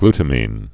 (gltə-mēn)